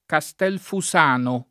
vai all'elenco alfabetico delle voci ingrandisci il carattere 100% rimpicciolisci il carattere stampa invia tramite posta elettronica codividi su Facebook Castel Fusano [ ka S t $ l fu S# no o ka S t $ l fu @# no ] top.